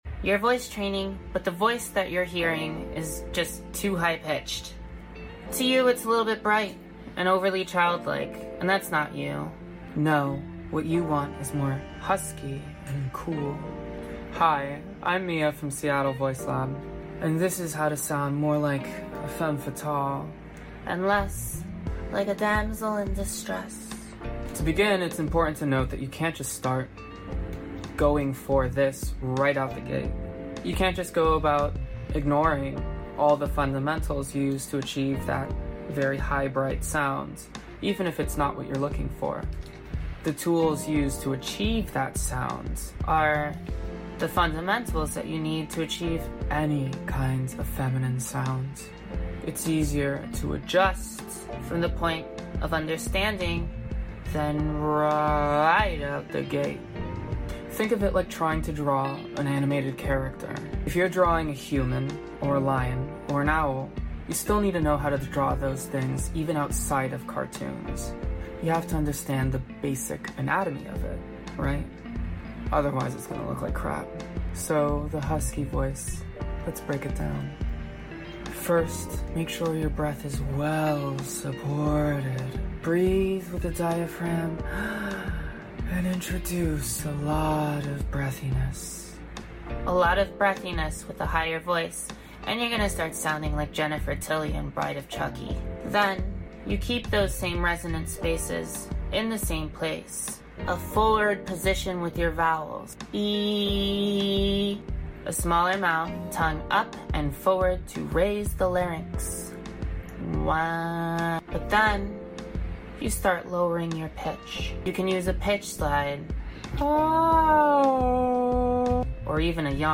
You’re voice training but you want something huskier rather than high and bright. 👄 It’s possible, you just need to start up and slide down.